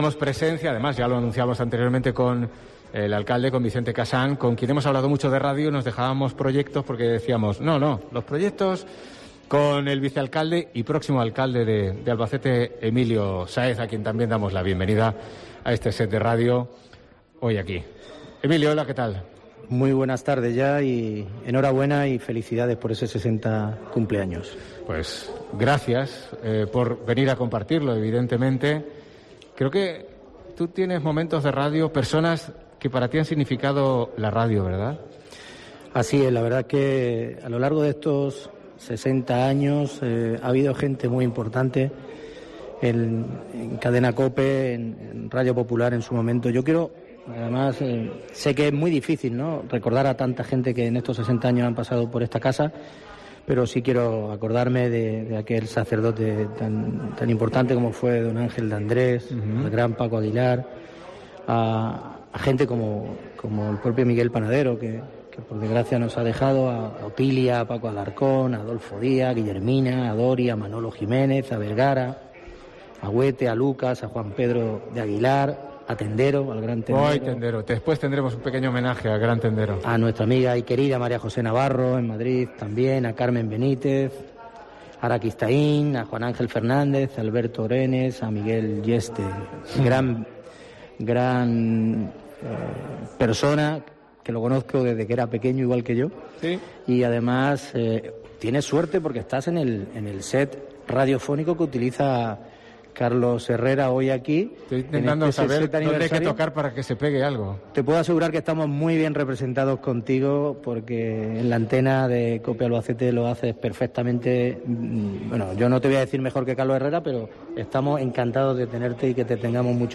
Entrevista a Emilio Saéz, vicealcalde de Albacete, en el 60 aniversario de Cope